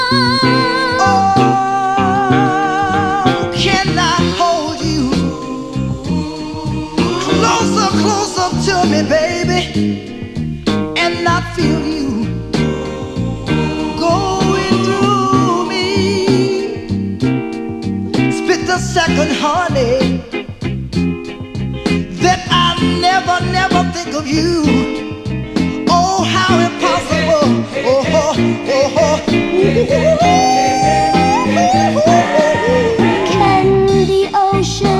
Жанр: Поп / R&b / Рок / Соул / Фанк